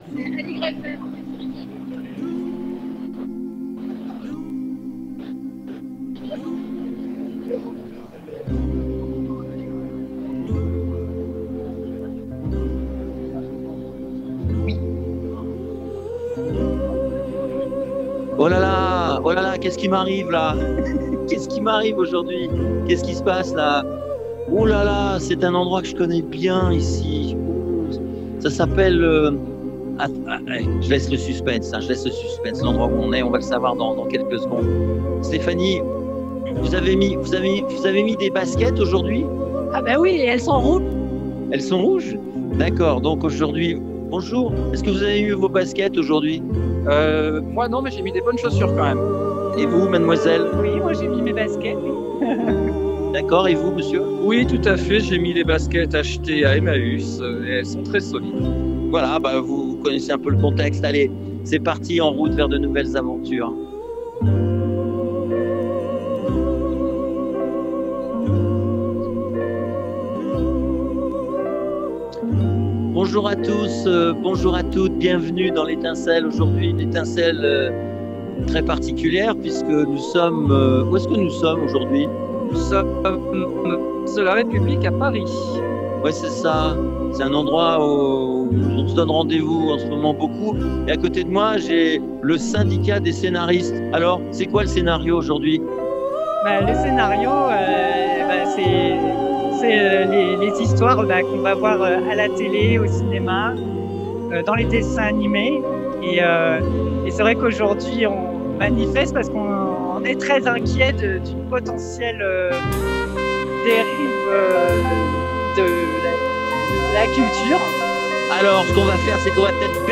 L'étincelle dans la ville s'est rendue à la manifestation de samedi à Paris et est allée à la rencontre de ceux qui se battent contre l'arrivée de l'extrême droite au pouvoir.